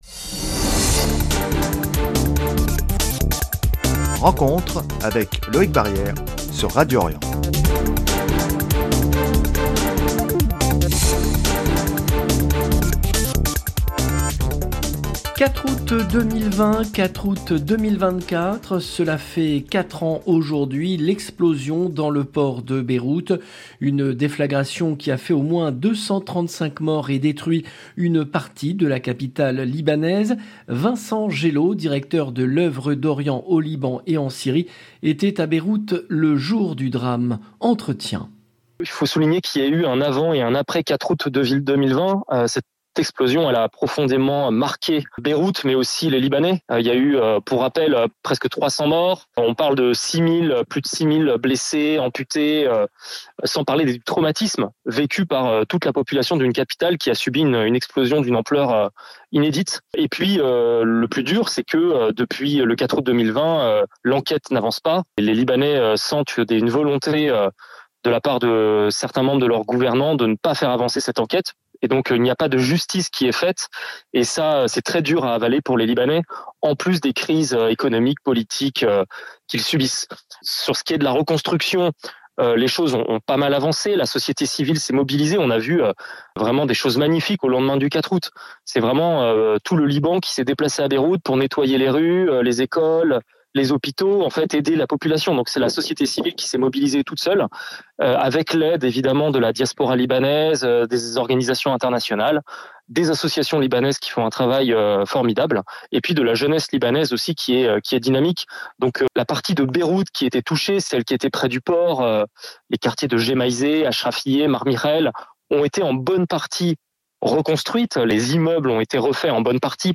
Entretien… 0:00 7 min 57 sec